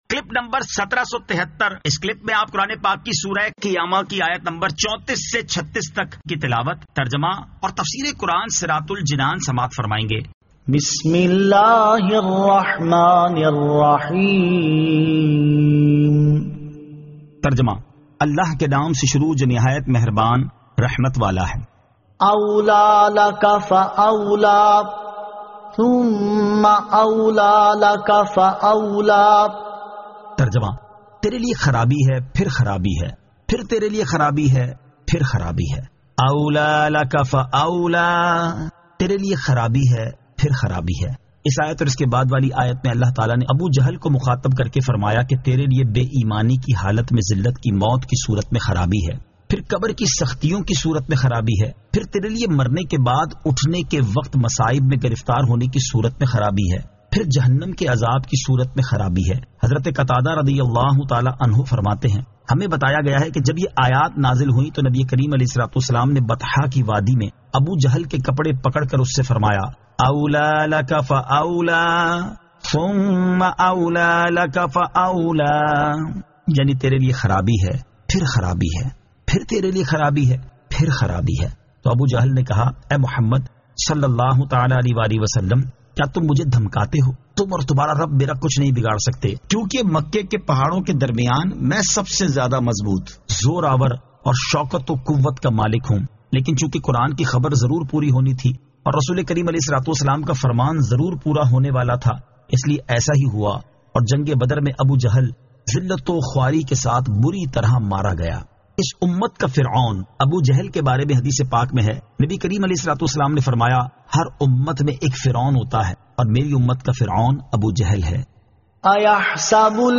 Surah Al-Qiyamah 34 To 36 Tilawat , Tarjama , Tafseer